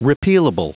Prononciation du mot repealable en anglais (fichier audio)
Prononciation du mot : repealable